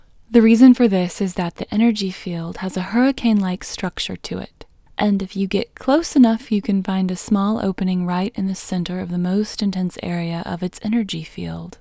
IN – the Second Way – English Female 6